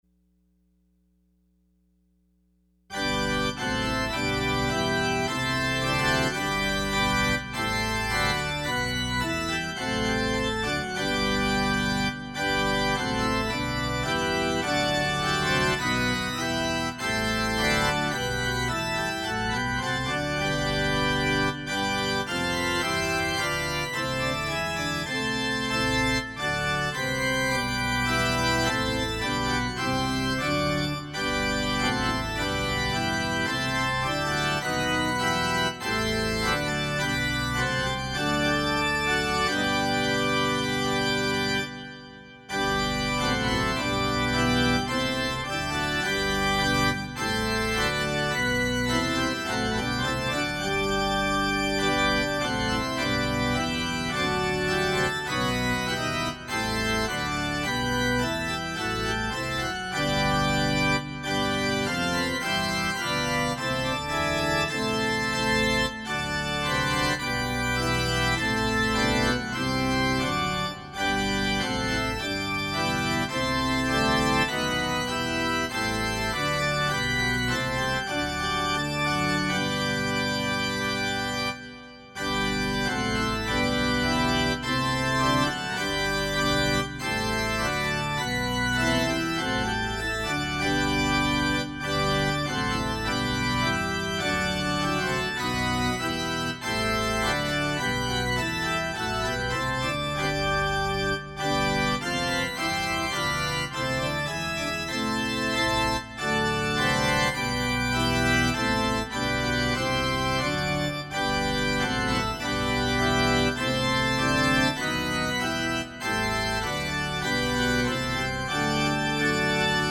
Opening Hymn – Hail thou once despised Jesus!